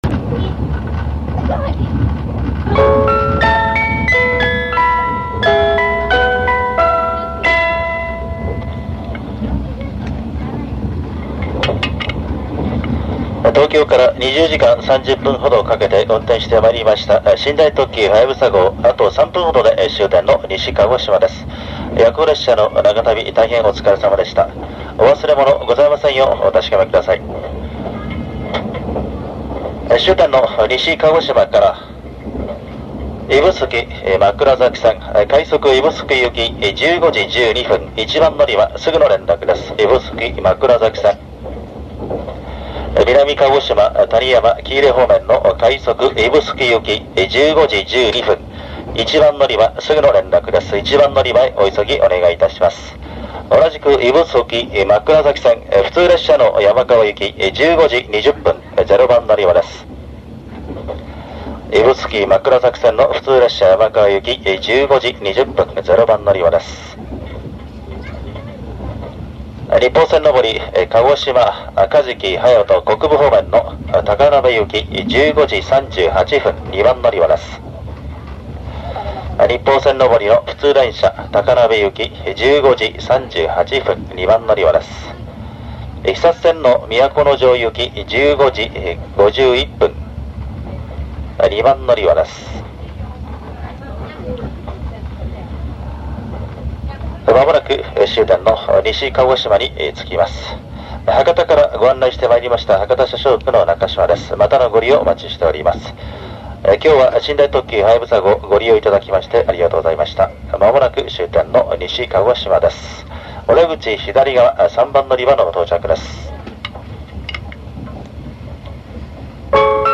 〜鹿児島   PC25 短縮前の終着駅、西鹿児島到着前の車内放送。
hayabusa_5_nishi-kagoshima.mp3